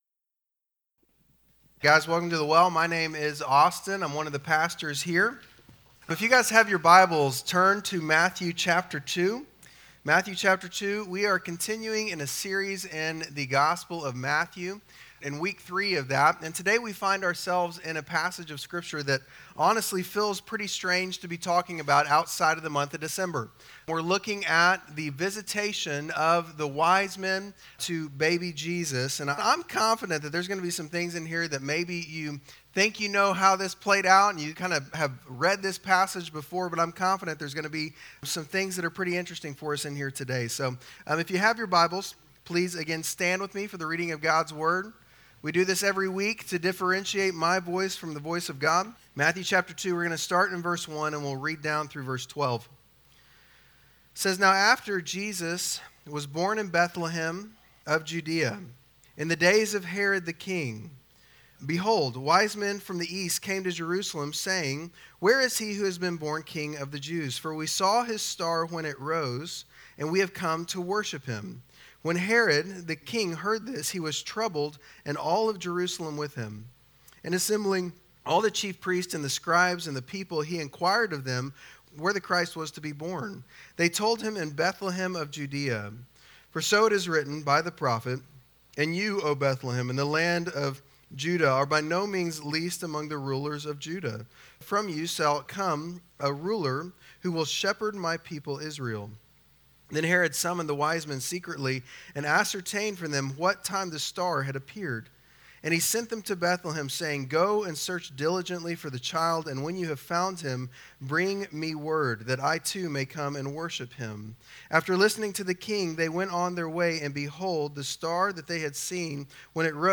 Bible Text: Matthew 2:1-12 | Preacher